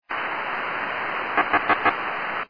> what sounds like a momentary burst of 4 data pulses.   It often
hf-data-4pluse.wav